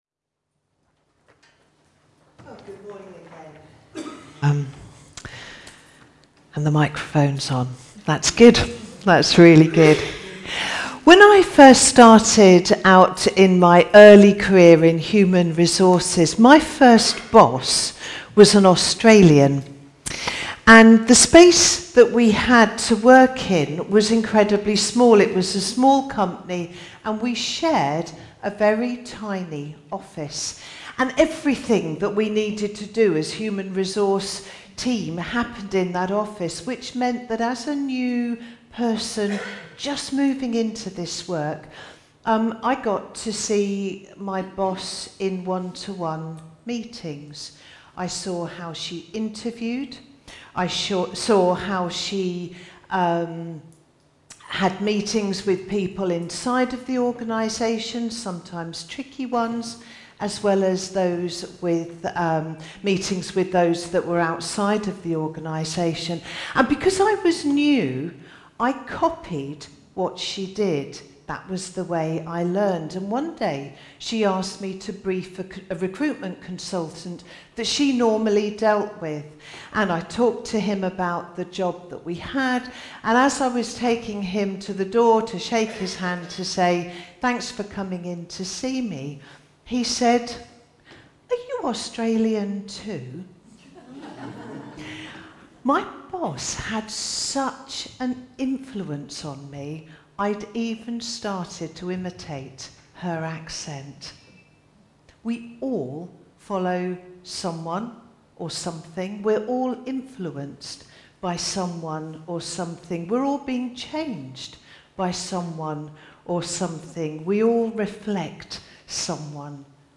A message from the series "Stand Alone Sermons 2025."